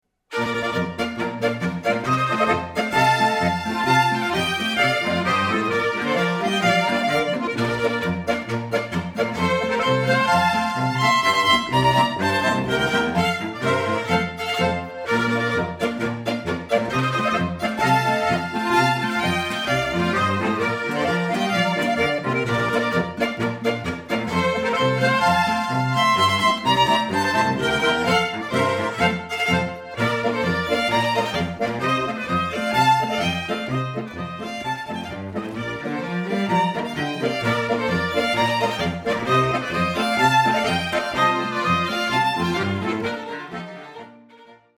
[20/1/2011]LEMPERBICHL- POLKA 奥地利 蒂罗尔民歌！